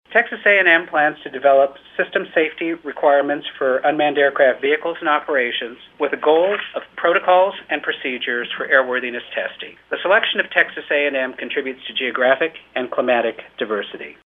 Click below for comments from the FAA’s Michael Hwerta, courtesy of Fox News Radio.